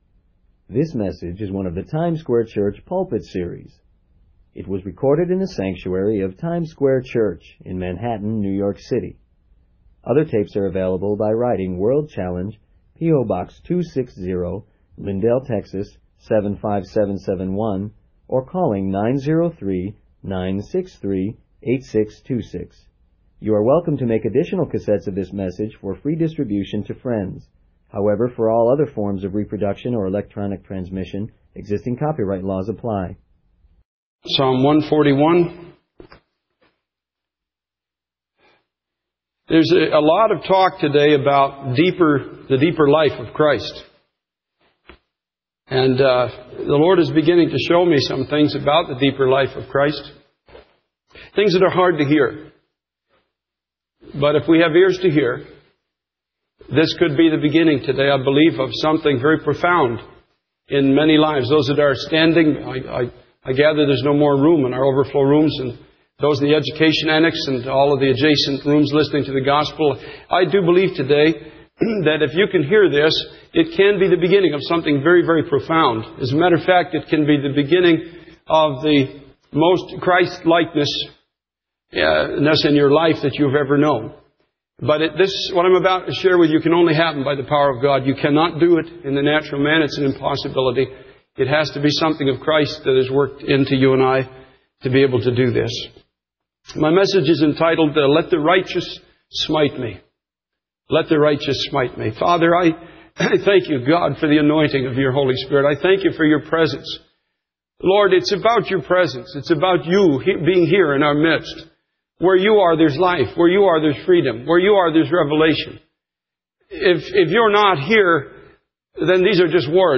In this sermon, the preacher emphasizes the theme of freedom in Christ.
It was recorded in the sanctuary of Times Square Church in Manhattan, New York City.